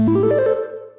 Techmino/media/effect/chiptune/spin_2.ogg at beff0c9d991e89c7ce3d02b5f99a879a052d4d3e
spin_2.ogg